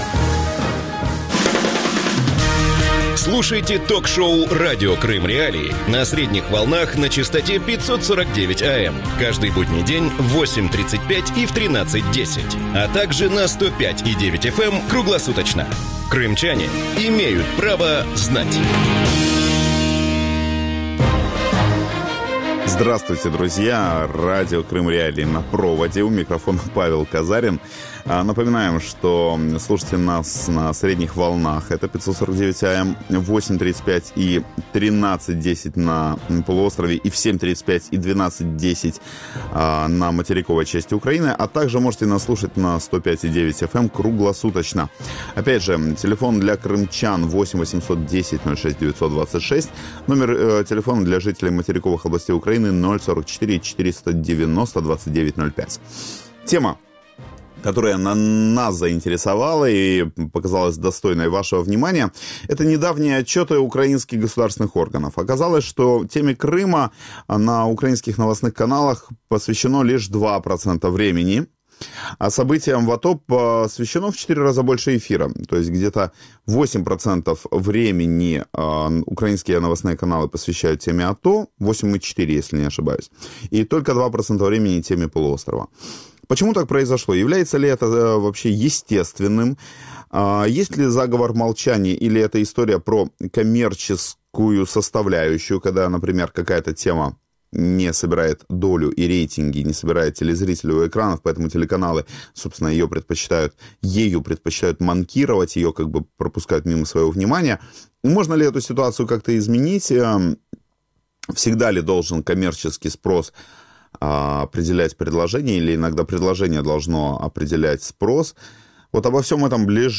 Есть ли государственная стратегия по вопросам информационной политики по Крыму? Гости эфира: Сергей Костинский член Национального совета по вопросам телевидения и радиовещания